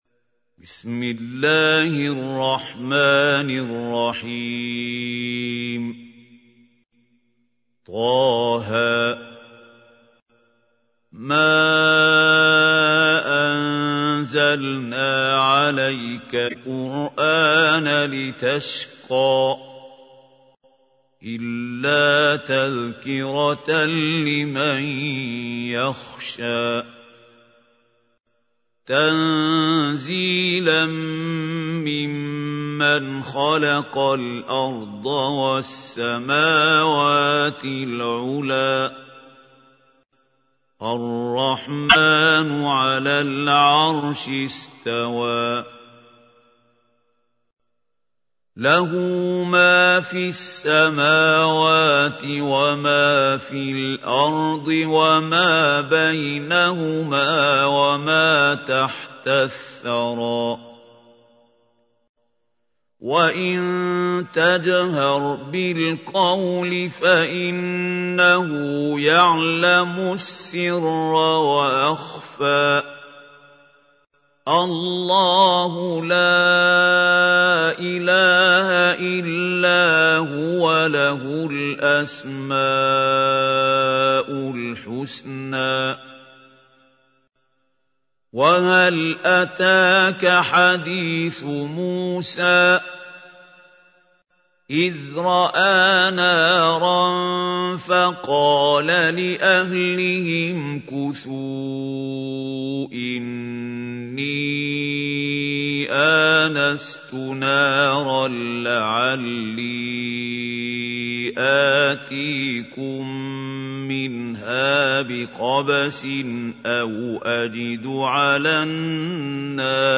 تحميل : 20. سورة طه / القارئ محمود خليل الحصري / القرآن الكريم / موقع يا حسين